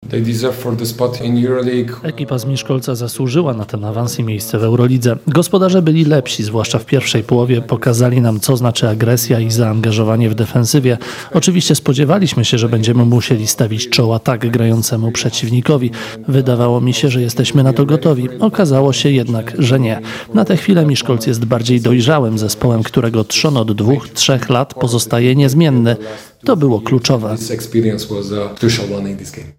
Na pomeczowej konferencji prasowej